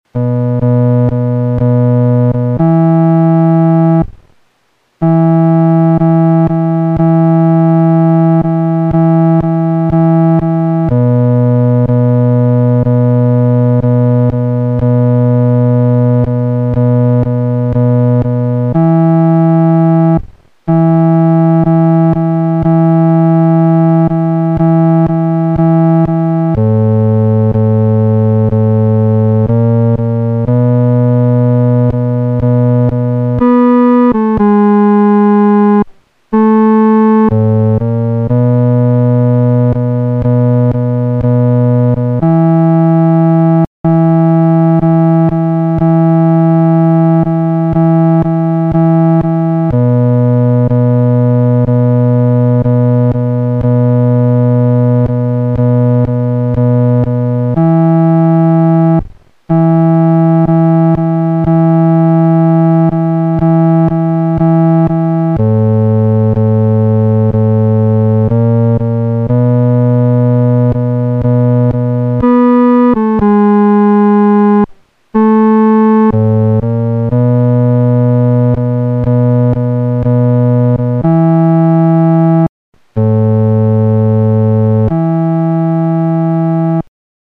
男低伴奏